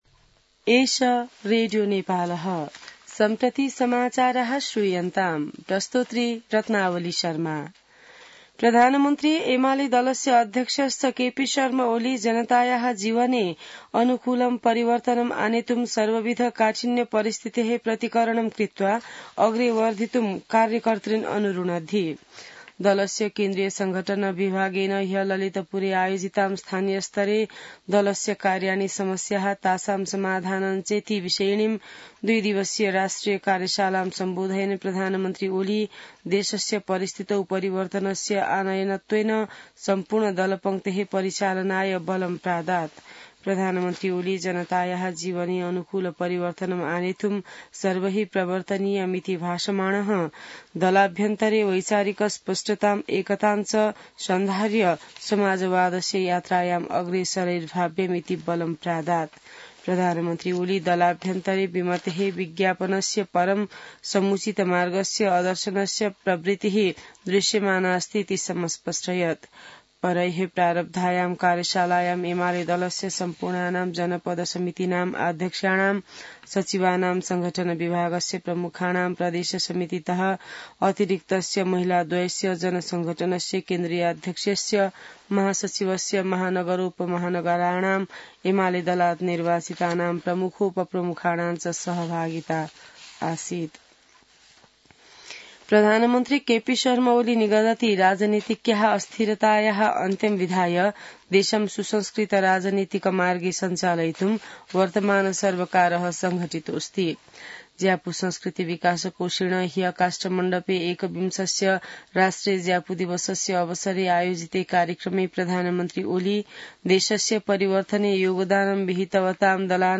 संस्कृत समाचार : २ पुष , २०८१